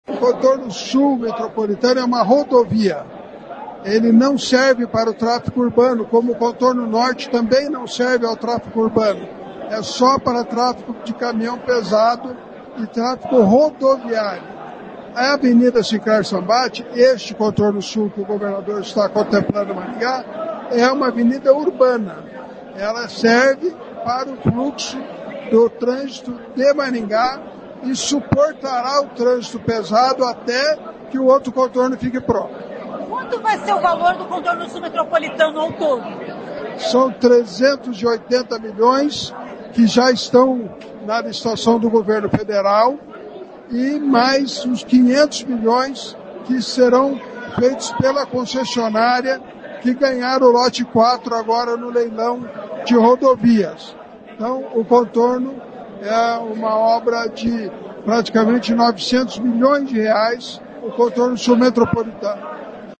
O deputado federal Ricardo Barros explica que o Contorno Sul Metropolitano é uma obra de cerca de R$ 900 milhões com recursos do Governo Federal e da concessionária que vencer a licitação do lote 4 de rodovias, em outubro. Ouça o que diz o deputado: